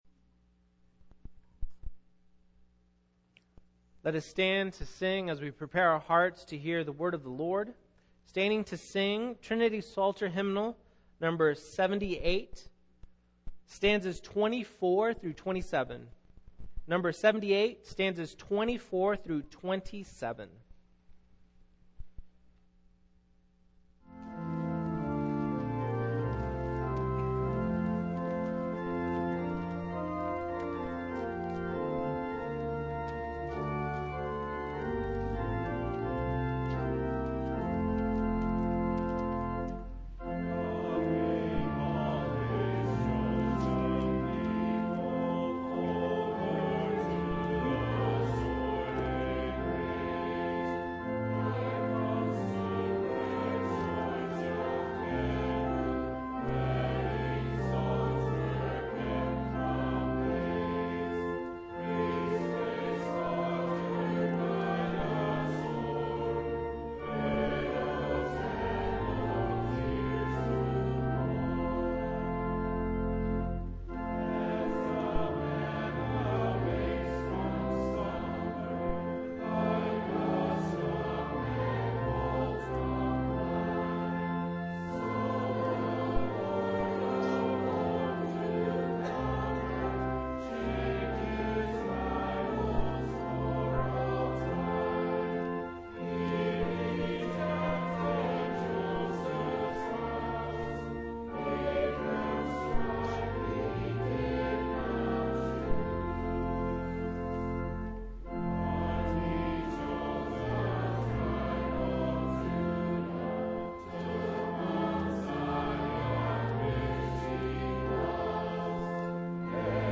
Series: Single Sermons
Service Type: Morning